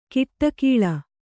♪ kiṭṭakīḷa